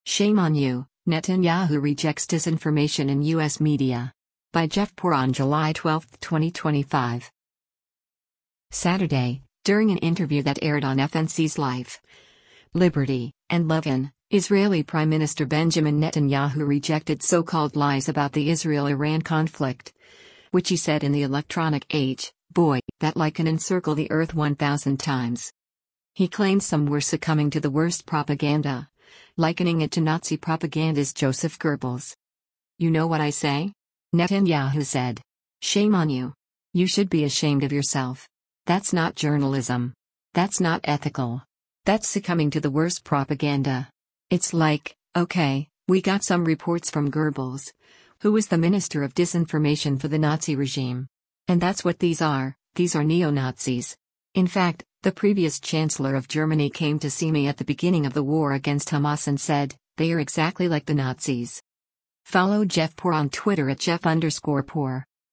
Saturday, during an interview that aired on FNC’s “Life, Liberty & Levin,” Israeli Prime Minister Benjamin Netanyahu rejected so-called “lies” about the Israel-Iran conflict, which he said “in the electronic age — boy, that lie can encircle the earth 1,000 times.”